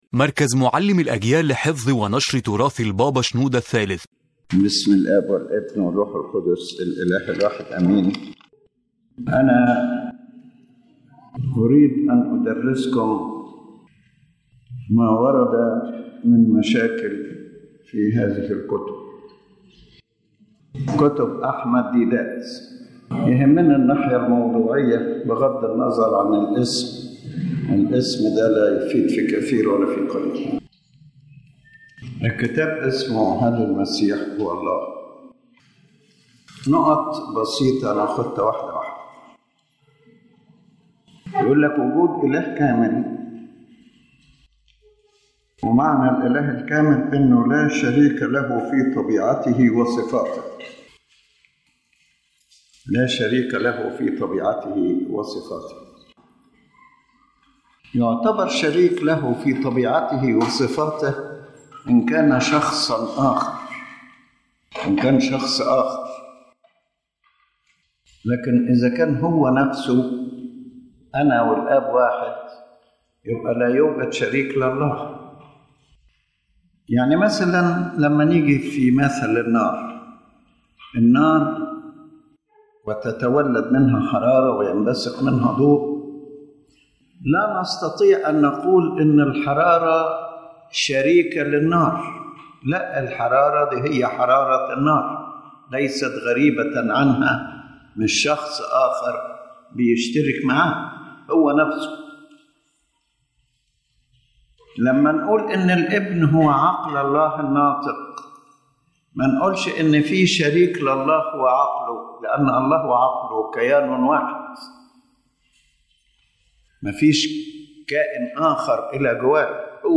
The lecture addresses doctrinal responses to the objections found in the book “Is Christ God?”, clarifying the Orthodox Christian understanding of the nature of Christ and distinguishing between what is said about Him according to divinity and according to humanity.